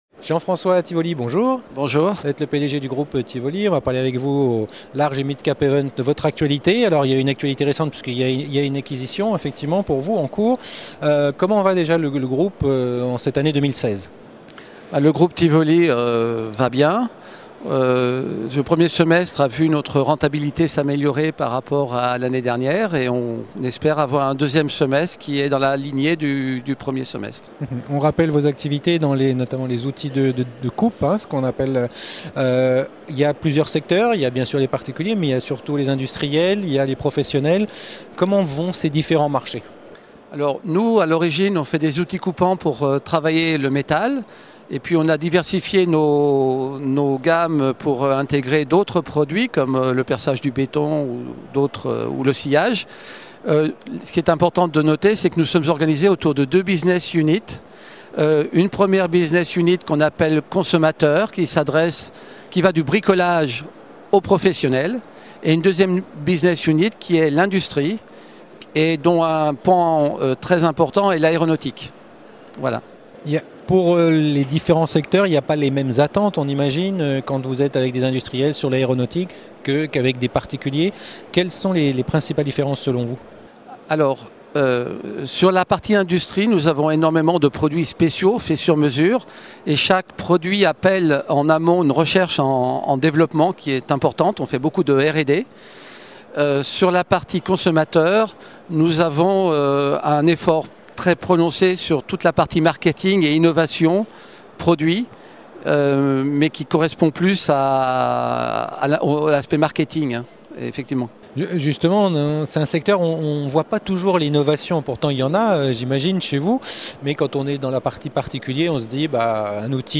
La Web TV au Midcap Event 2016 organisé par CF&B au Palais Brongniart